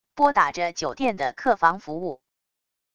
拨打着酒店的客房服务wav音频